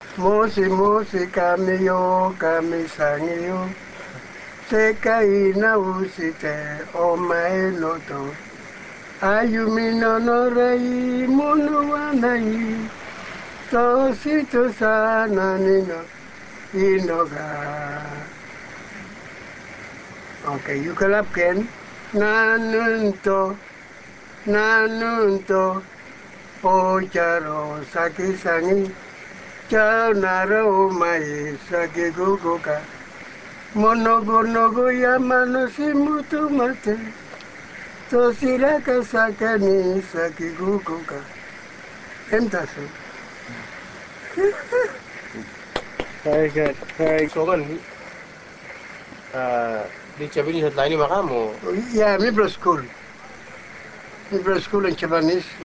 Real Audio  Japanese Songs
Song #1 (Children's Song)